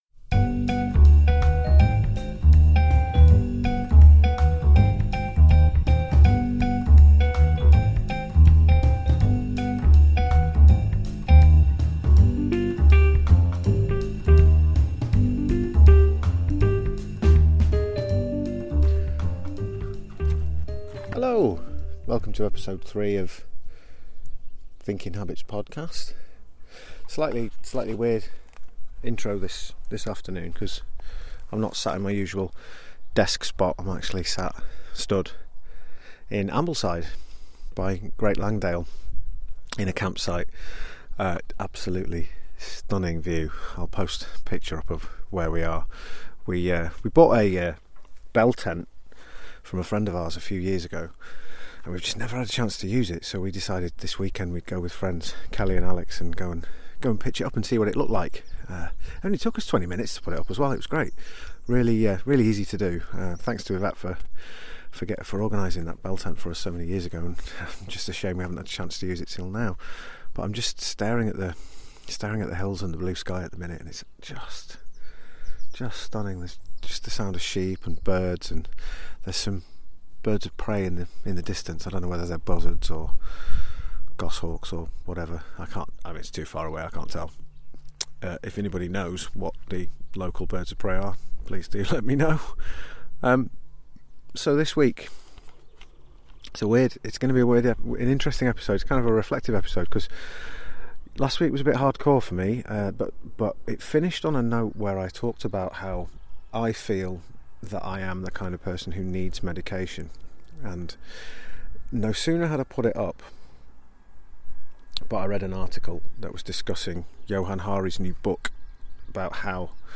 A two-stop podcast this week, with the intro done stood in a field.